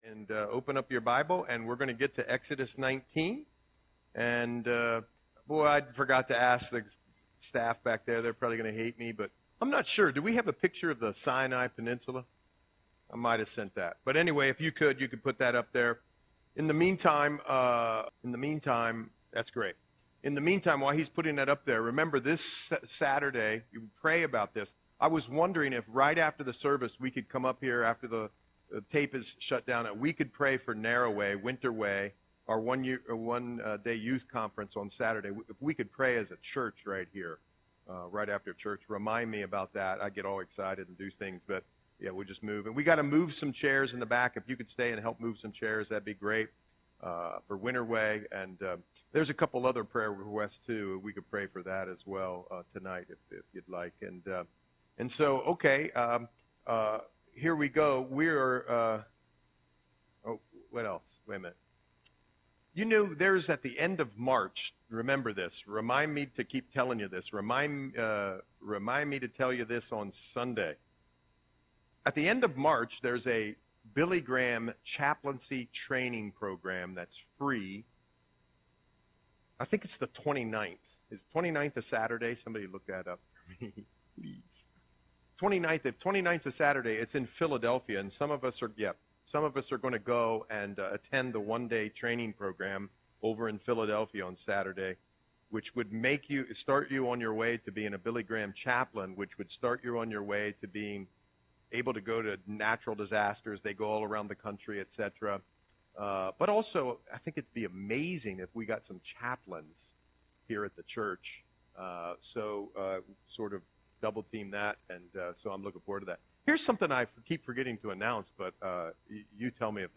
Audio Sermon - February 26, 2025